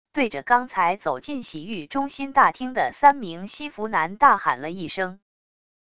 对着刚才走进洗浴中心大厅的三名西服男大喊了一声wav音频生成系统WAV Audio Player